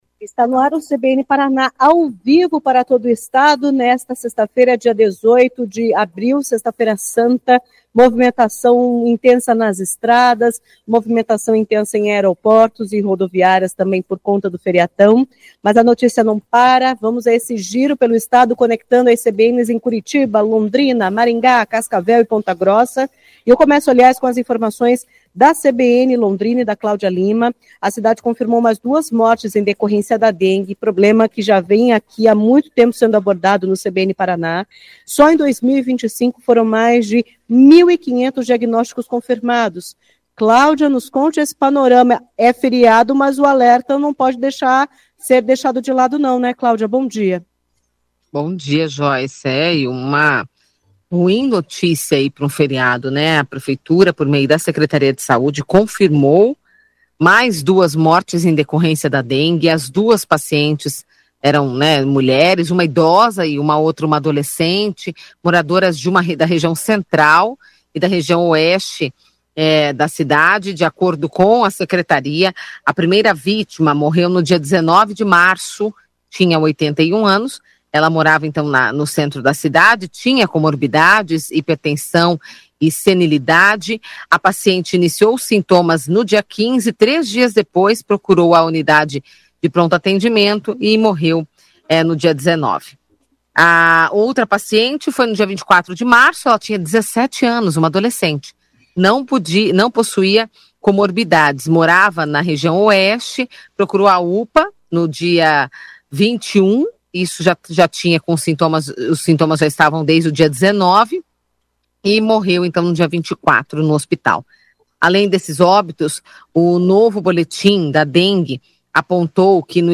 O CBN Paraná vai ao ar de segunda a sexta-feira, a partir das 10h35, com participação da CBN Maringá, CBN Curitiba, CBN Londrina, CBN Cascavel e CBN Ponta Grossa.